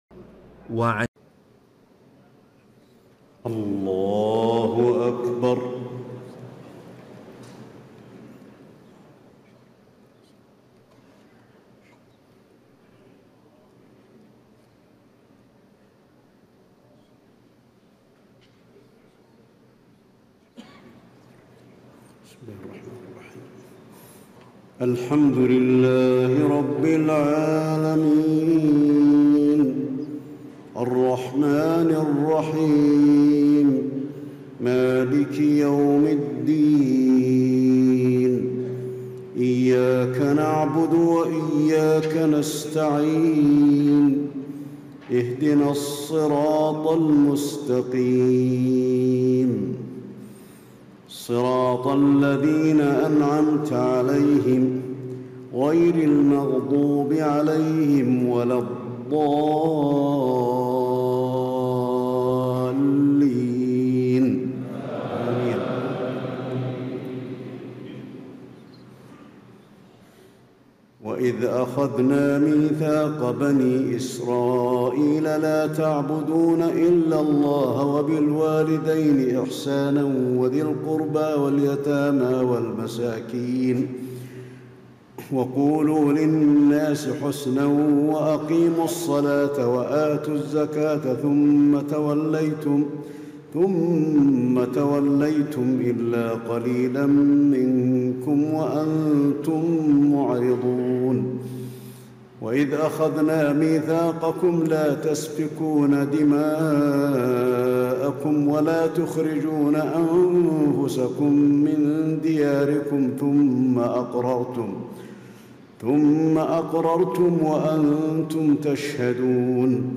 تهجد ليلة 21 رمضان 1436هـ من سورة البقرة (83-141) Tahajjud 21 st night Ramadan 1436H from Surah Al-Baqara > تراويح الحرم النبوي عام 1436 🕌 > التراويح - تلاوات الحرمين